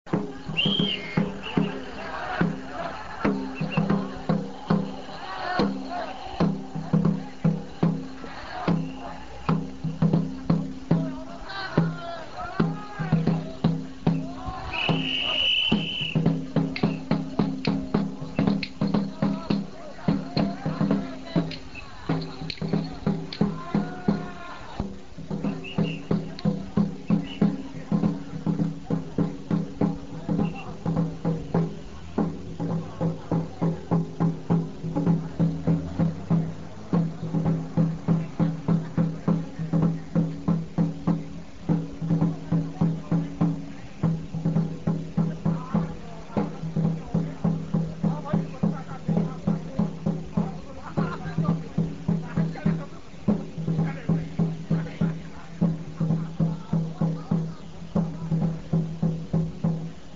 だんじりの太鼓
豊岡の10月のだんじり祭りの太鼓です、小学生の部と中年会の部があり、市内をねり歩きます。 (音は中年会の部です、昭和53年録音)